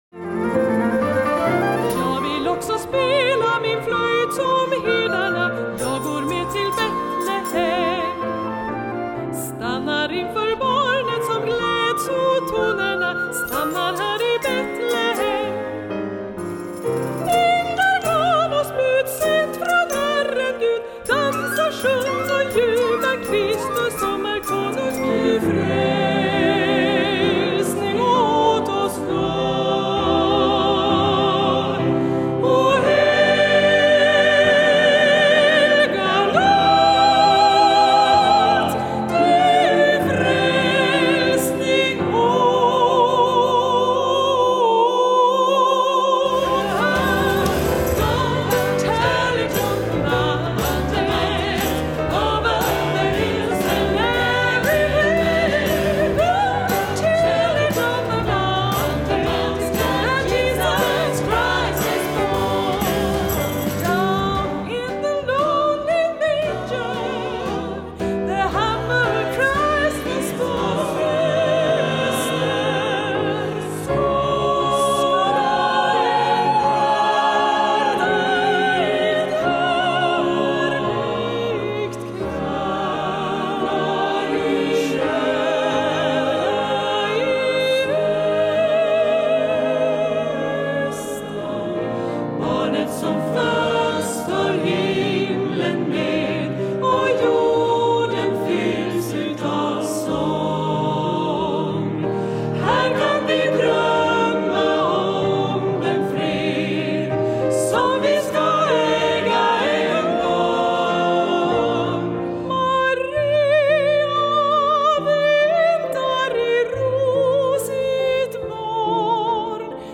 solosång, kör och instrumentalister.